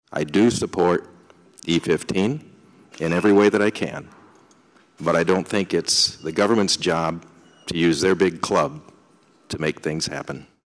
Republican Senator Dennis Guth, a farmer from Klemme, says the mandate violates the principles of free enterprise.